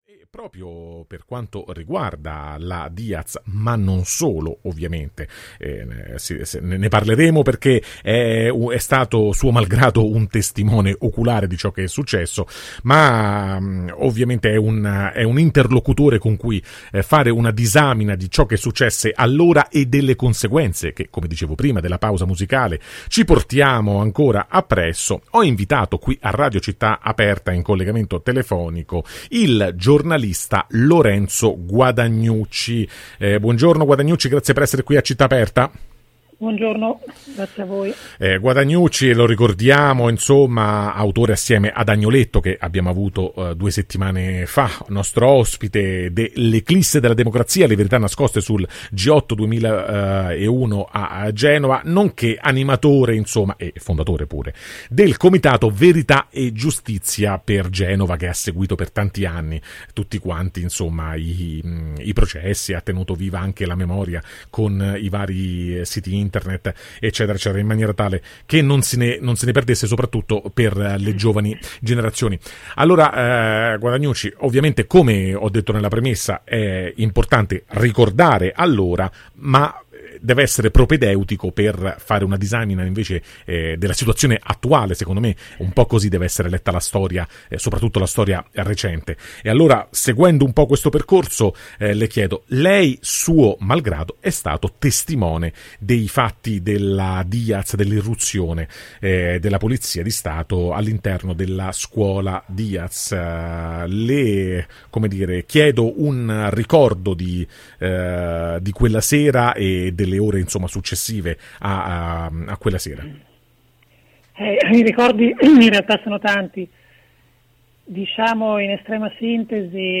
“Avevamo ragione noi” – verso Genova 2001 [Podcast intervista